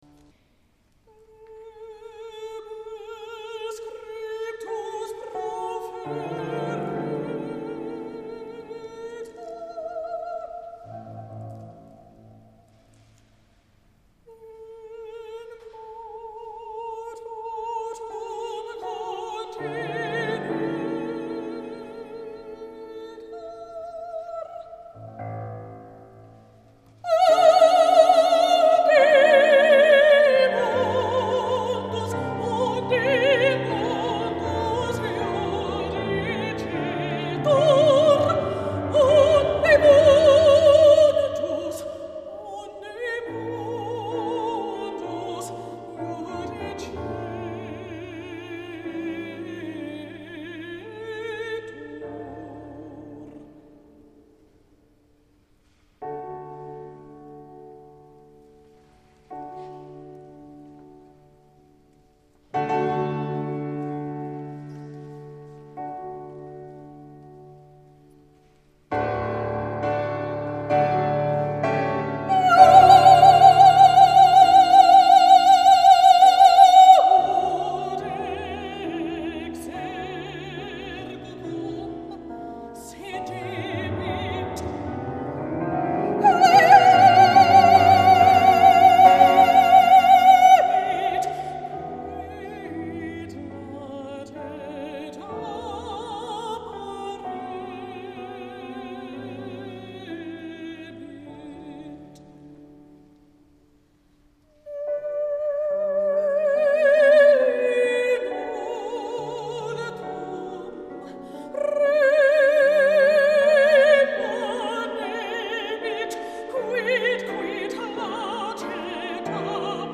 Mirepoix Cathedral